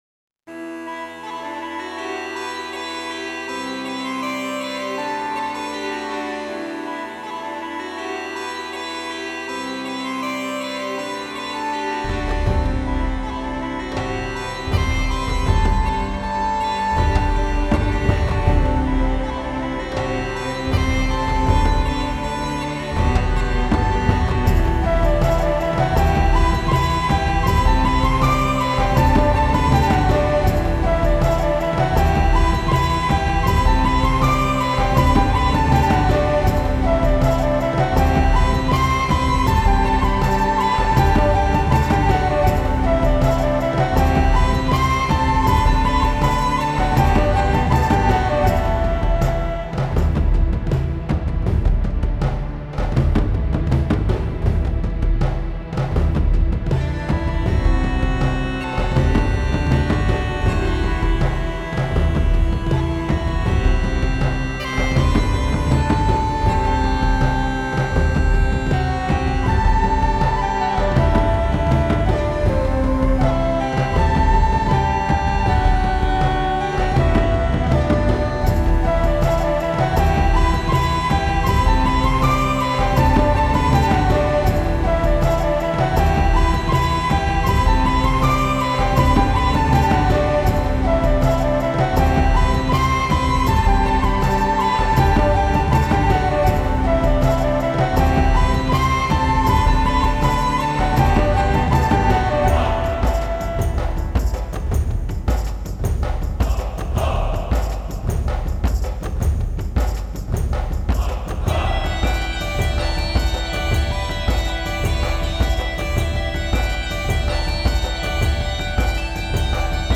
Мистическая музыка Gothic Metal Кельтская музыка